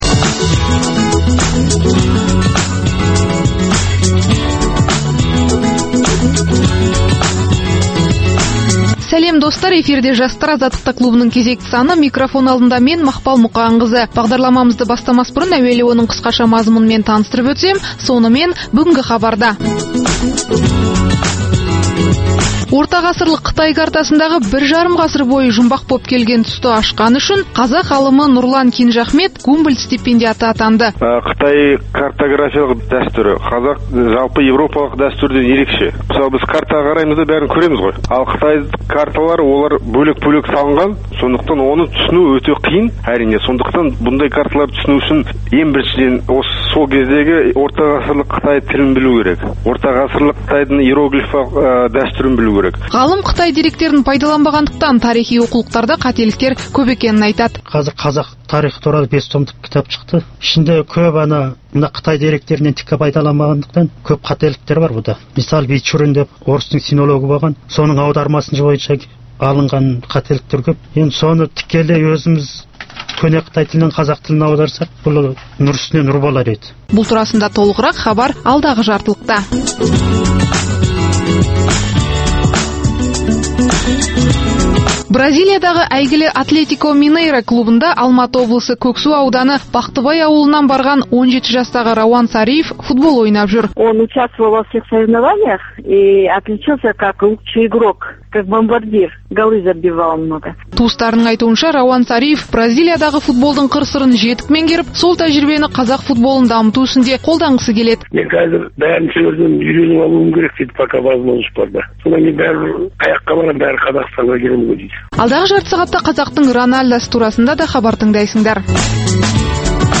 Пікірталас клубы